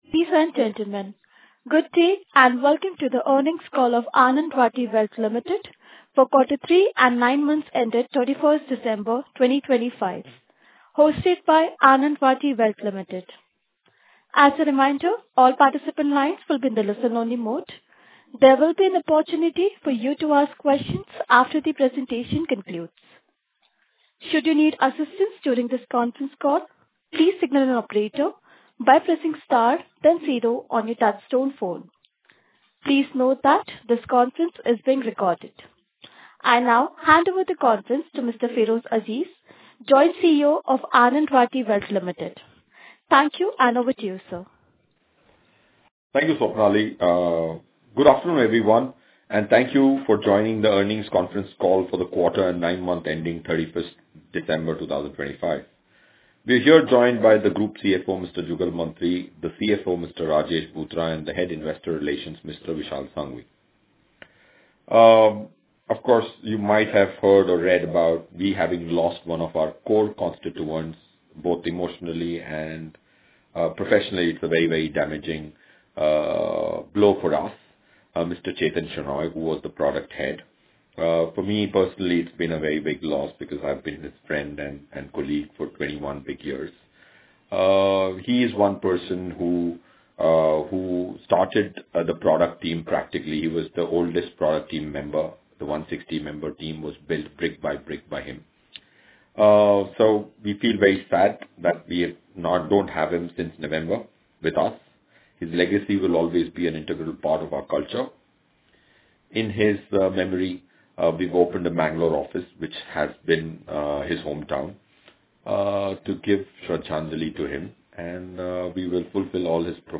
Earnings Call Recording Published
Anand Rathi has released the audio recording of its Q3 & 9M FY26 Earnings Conference Call.